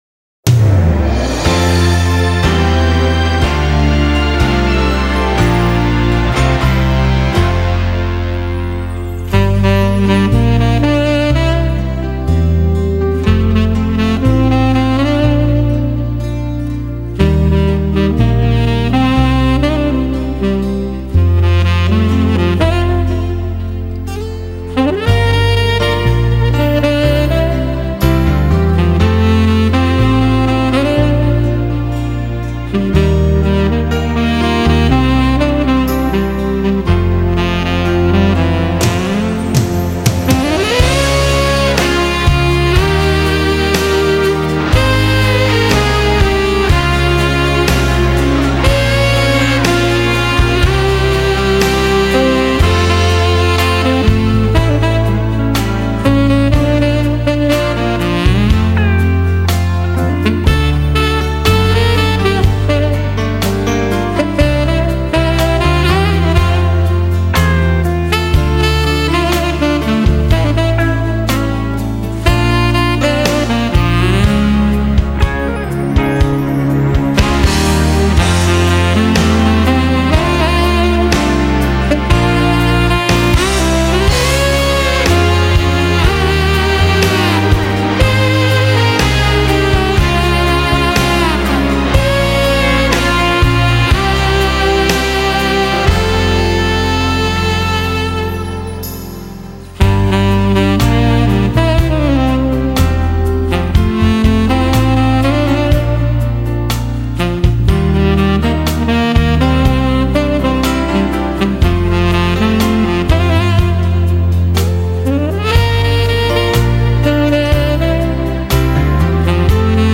柔情薩克斯風
他..給我們貼心的撫慰 他..給我們萬般的沉醉 他..讓薩克斯風音化為一種酣甜的幸福氣味